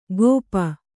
♪ gōpa